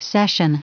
Prononciation du mot cession en anglais (fichier audio)
Prononciation du mot : cession